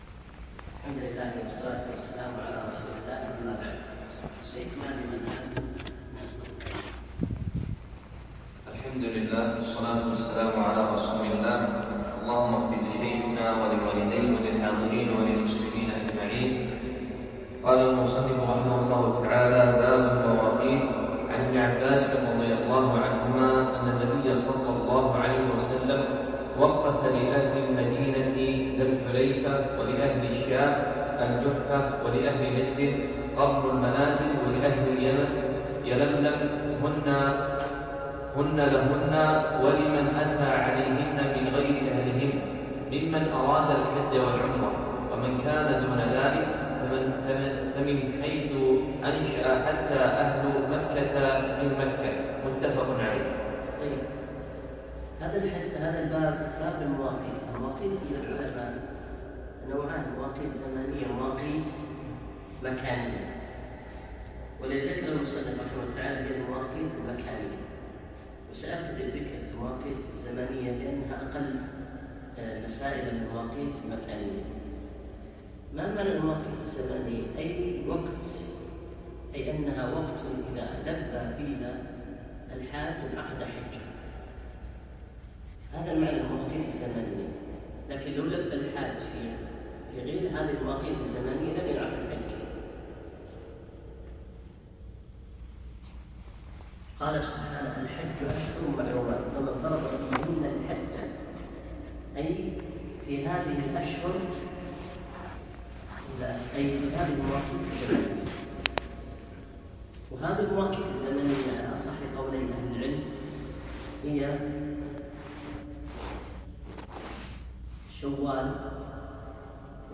يوم الأثنين 9 شوال 1438 الموافق 3 7 2017 في مسجد زين العابدين سعد العبدالله